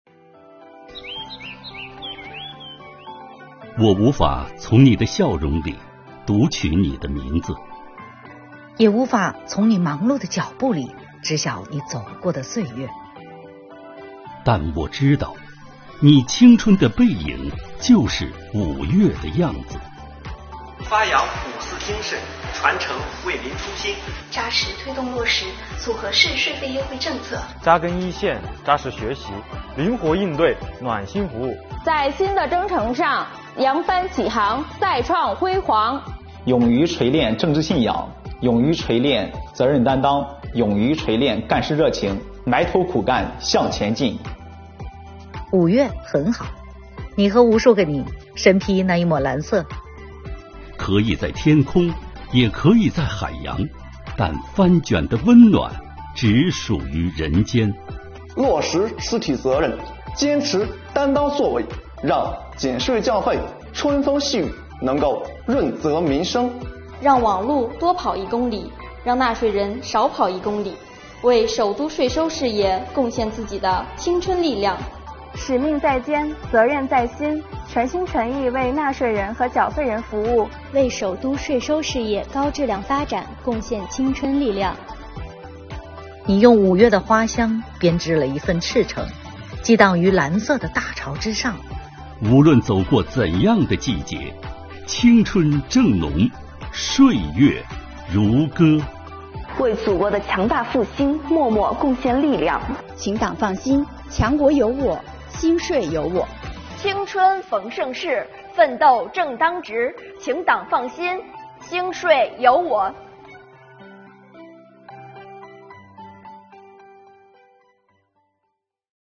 在北京，五四青年节到来之际，国家税务总局北京市税务局、北京广播电视台联合制作短片《五月的样子——致青年税务干部》，邀请青年干部们讲述自己在平凡岗位上的感悟，发出新时代税务青年的铿锵誓言。